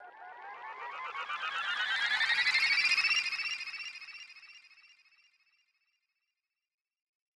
Transition [Reversed Water Splash Down].wav